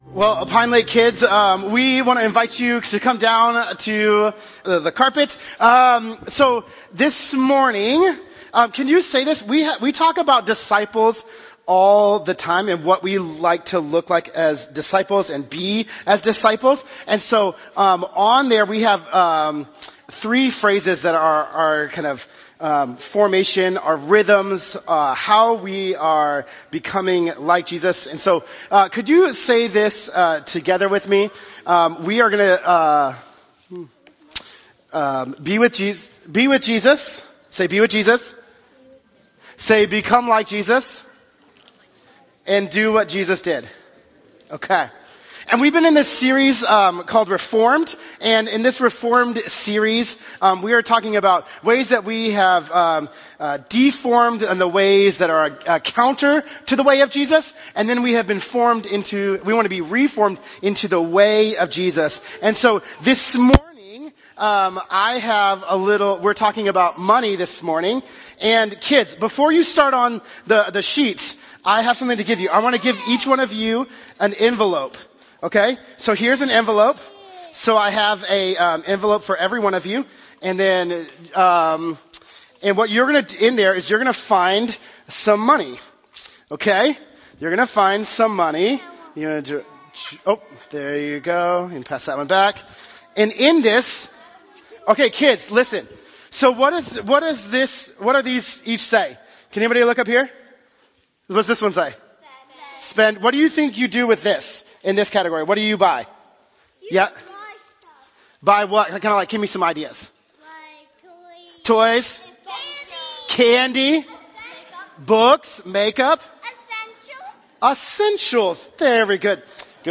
This Sunday is our monthly Discipleship Sunday, when we take time to learn as a community and together be formed in the way of Jesus.